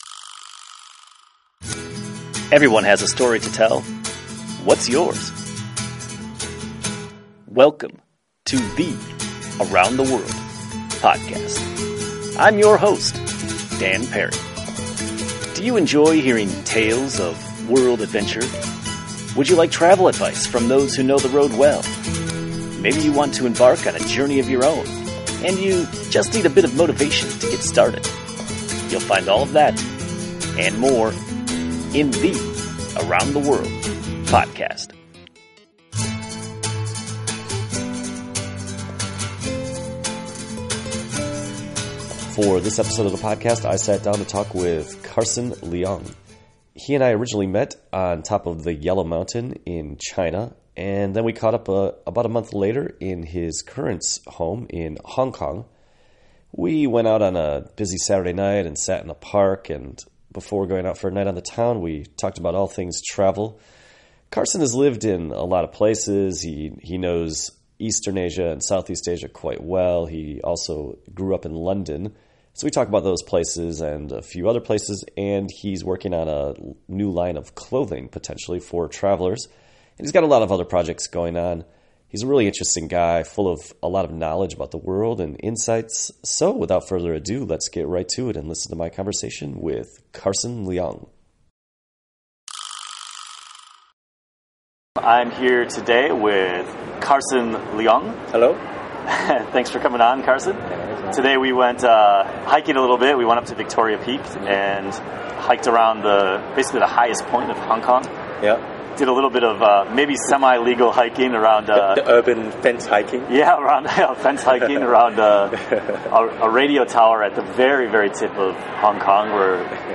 On a Saturday night in a lively park in the middle of the city, we spontaneously sat down to record one of our conversations. We touched on a variety of subjects, from travel in Vietnam and China, to designing comfortable clothing, to podcasting and photography.